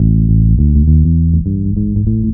TR BASS 1.wav